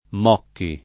[ m 0 kki ]